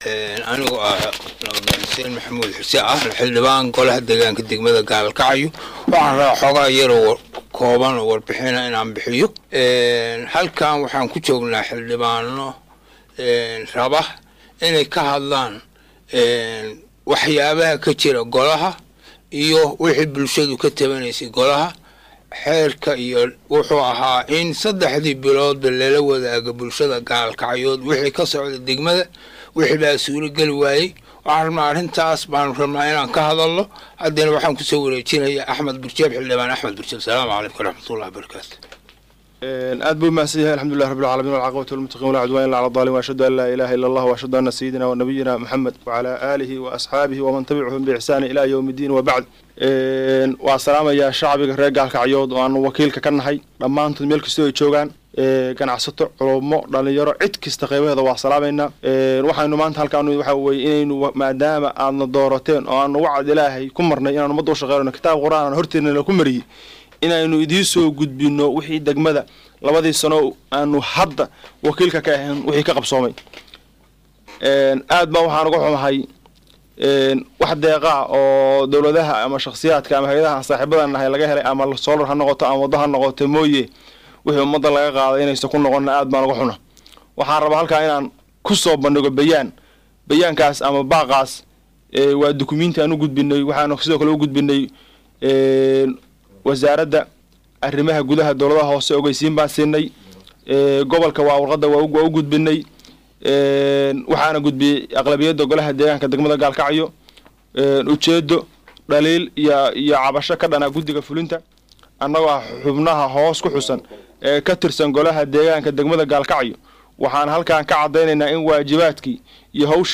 Xildhibaan ka tirsan golaha deegaanka Gaalkacyo oo lugu magaacabo Axmed Birjeed oo saxaafadda u akhriyay qoraalkaas ayaa wuxuu sheegay in la lunsaday dhammaan hantidii ummadda, waxuuna intaas kusii darey in lacagaha la lunsaday ay ka mid tahay mushaarkii iyo gunooyinkii shaqaalaha ee toddobadii bilood ee la soo dhaafay oo dhan 5,460,000,000 oo lacagta shillinka Soomaaliga ah.